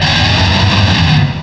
sovereignx/sound/direct_sound_samples/cries/druddigon.aif at master